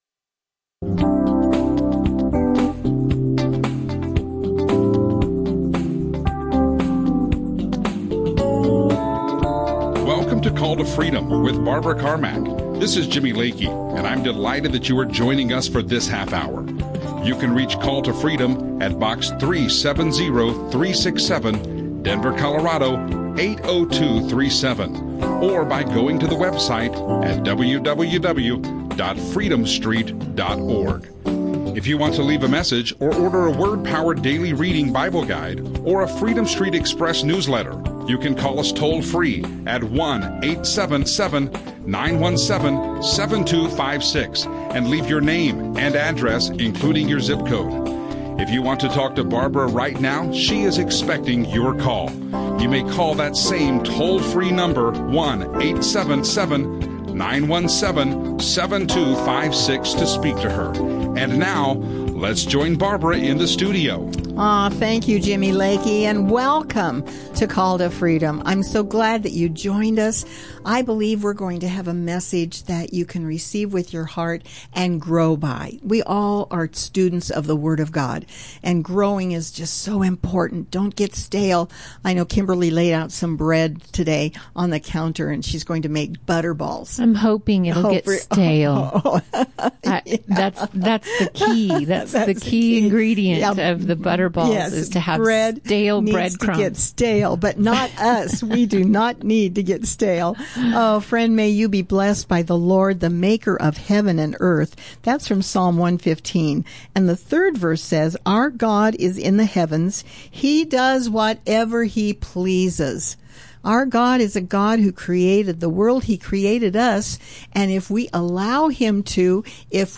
Christian radio show